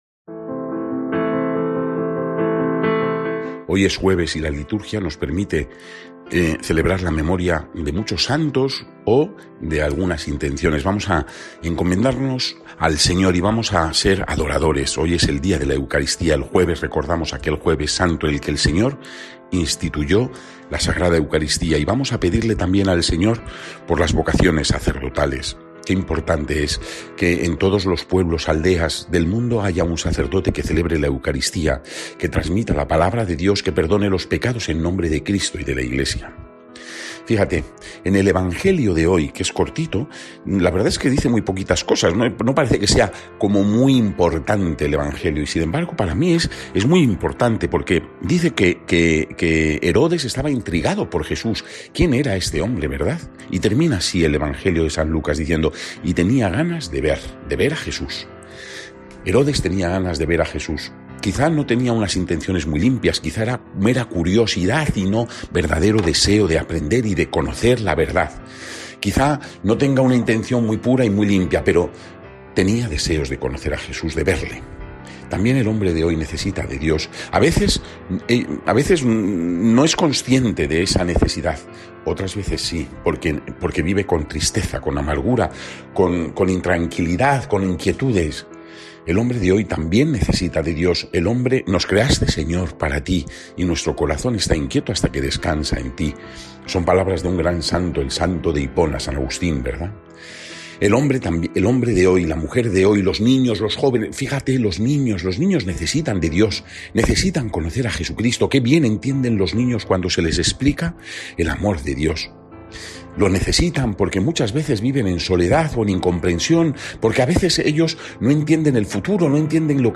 Meditación